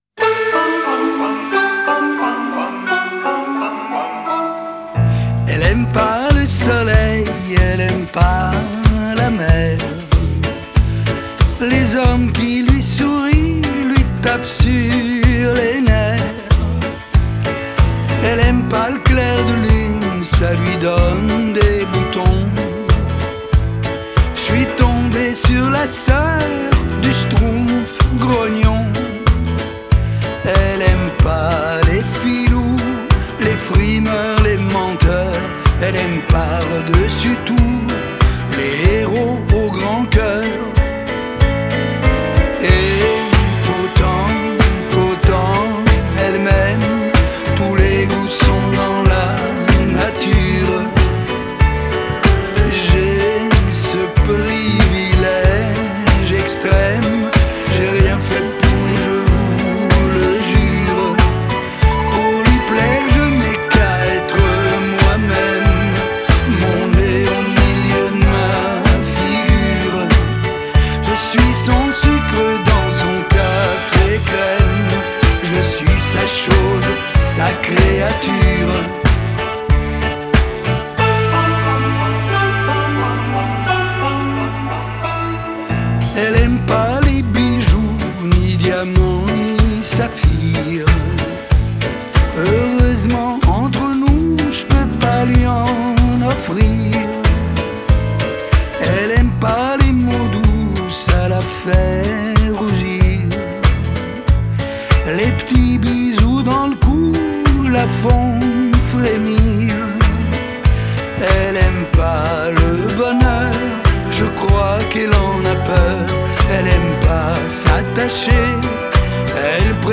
Genre: French Pop, French Chanson